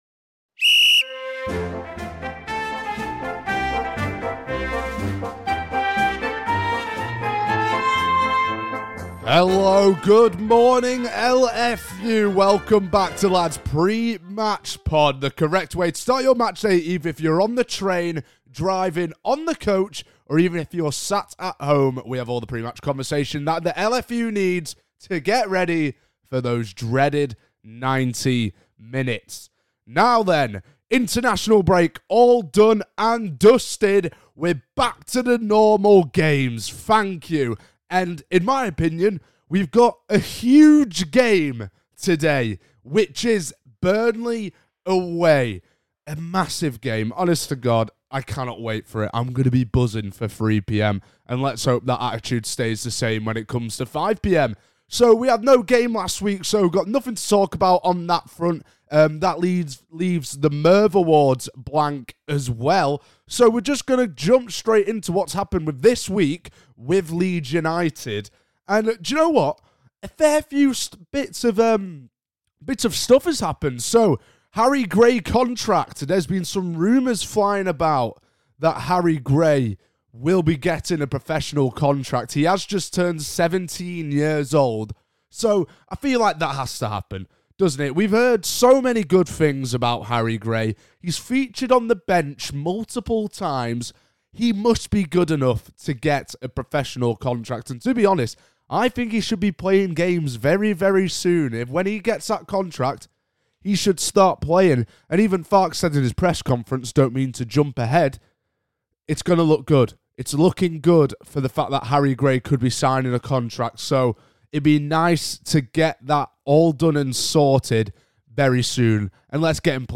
L2L IS BACK but no Dad just Lad this time…